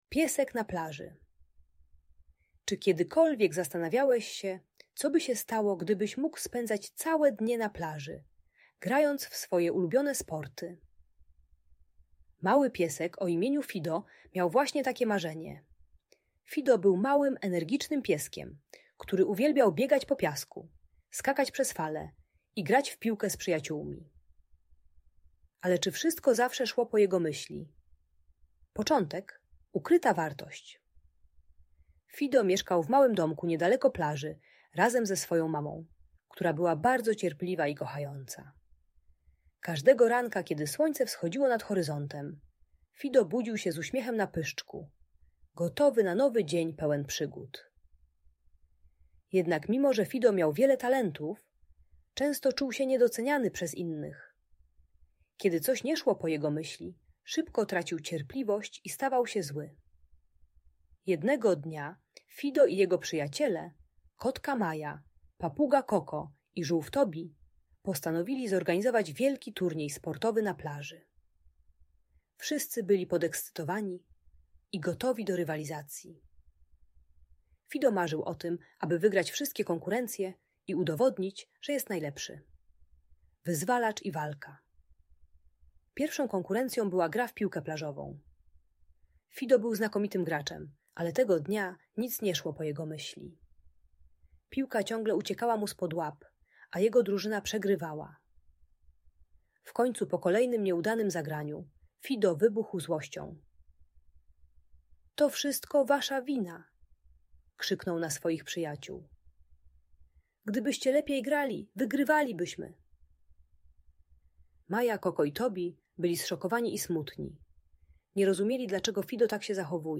Historia pieska Fido na plaży - opowieść o przyjaźni i zrozumieniu - Audiobajka